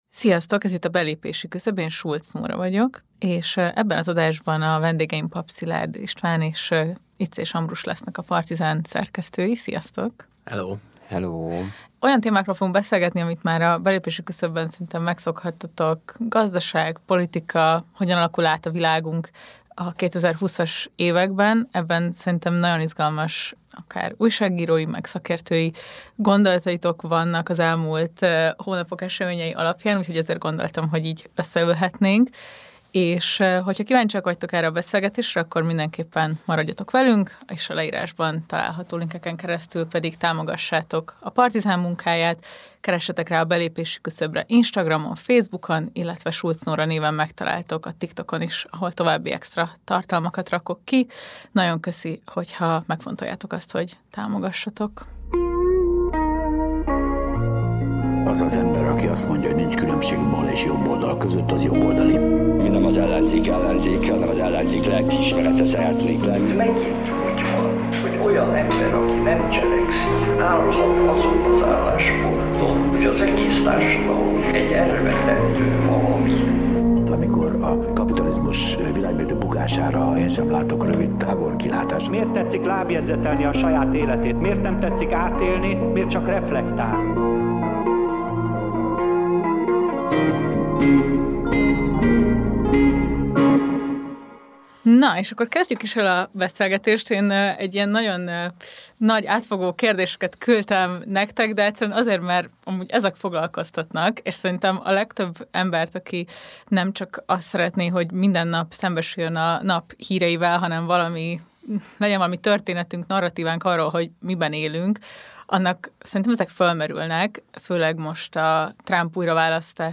Legújabb epizódunkban a Magyar Posta rendszerváltás utáni történetéről beszélgetünk